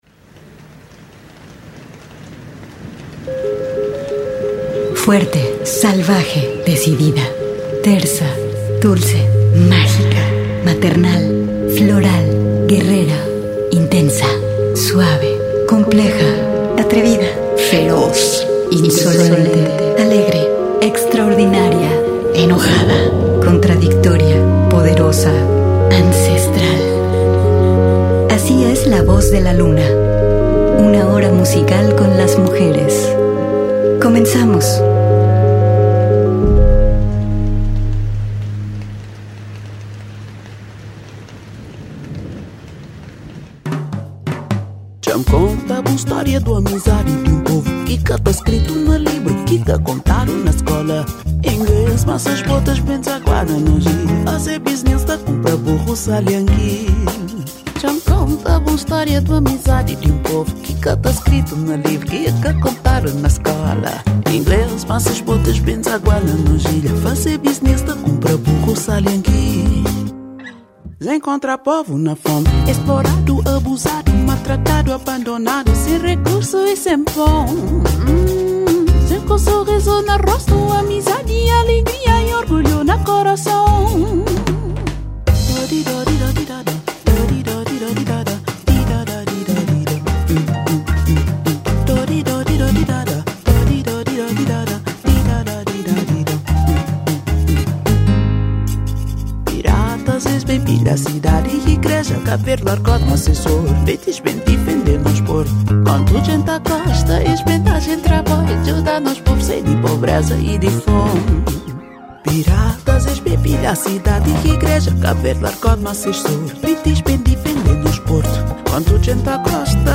mu´sica que hacen las mujeres en todas las latitudes del planeta Sintoniza el 104.3FM